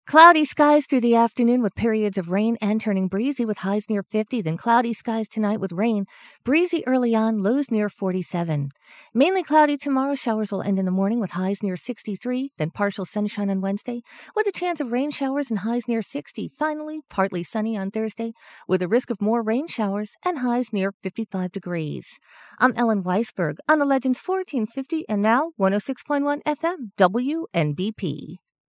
HFS provides high quality on-air weather reports in both English and Spanish.